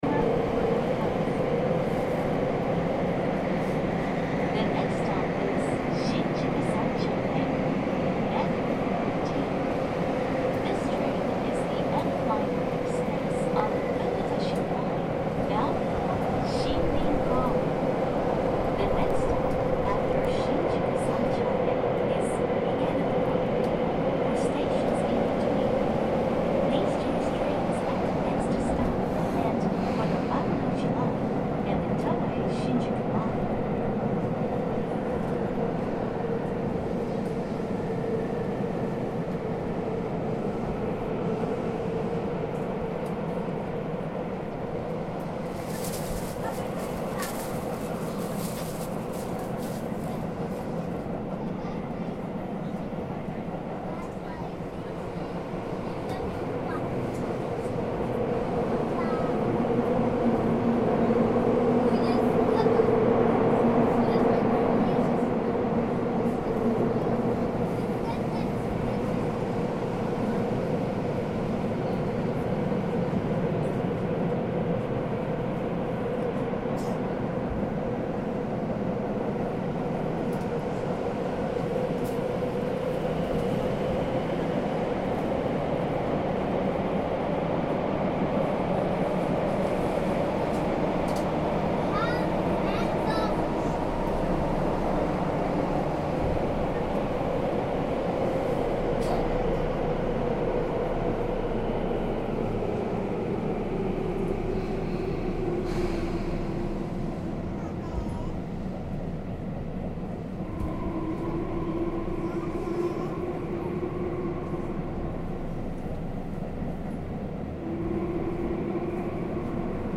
On board the Tokyo subway
This is the sound recorded on a train on the Fukutoshin line of the Tokyo subway - tune in for station announcements, the gentle hum of the engines rising and falling as we enter and leave stations, and onboard announcements.